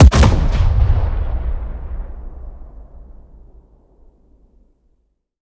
weap_rpapa7_fire_plr_atmo_03.ogg